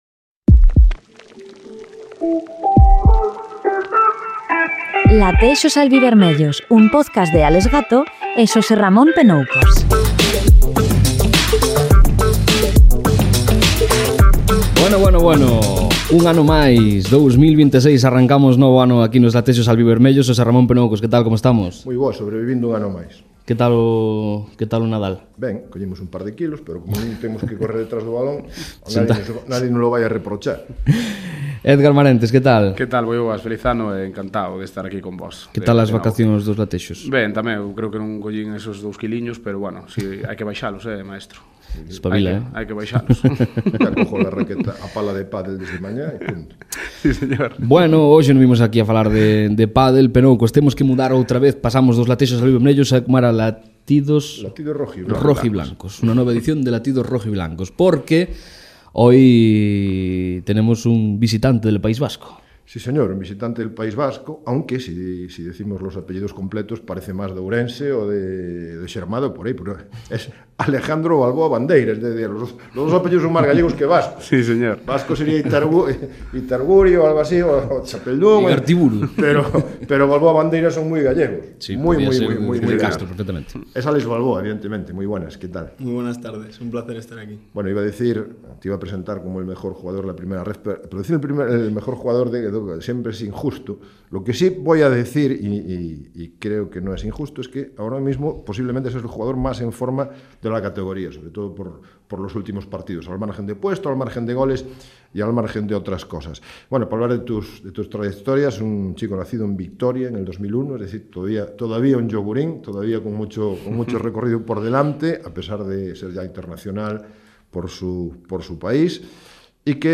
visitou os estudos de Radio Lugo Cadena SER para contar a súa historia.